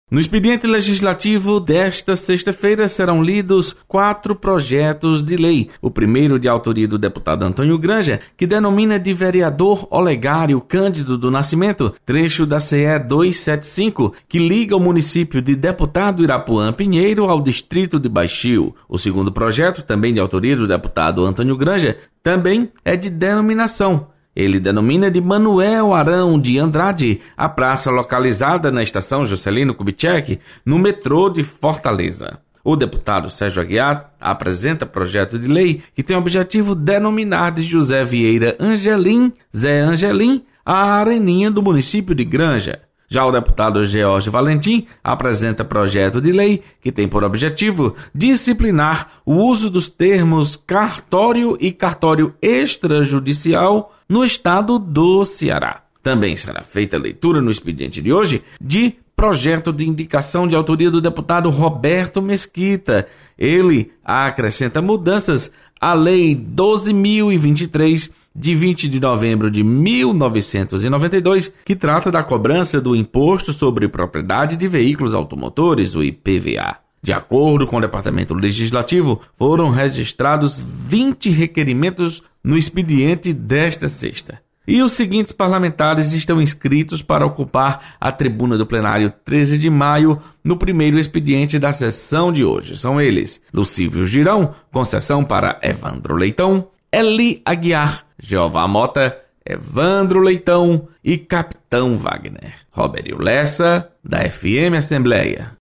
Acompanhe as informações do expediente legislativo desta sexta-feira. Repórter.